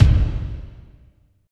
36.05 KICK.wav